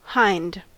Ääntäminen
US
IPA : /haɪnd/